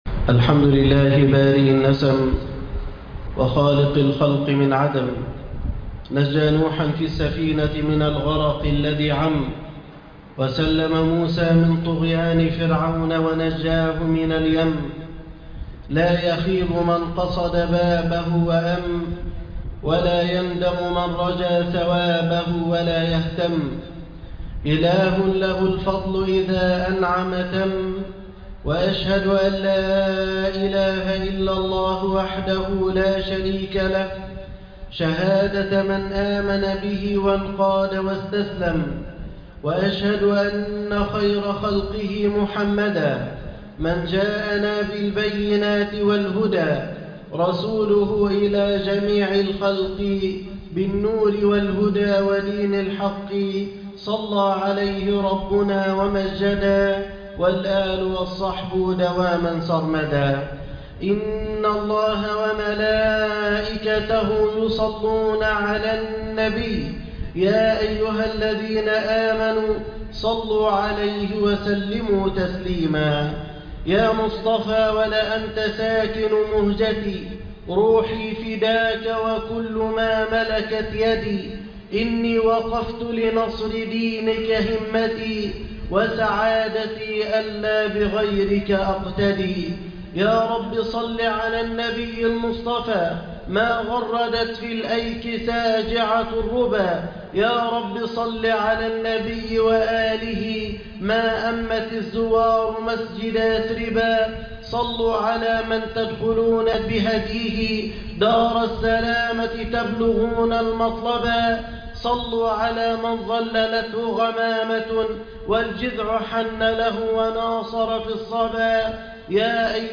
في ظلال السيرة النبوية الخطبة الأولى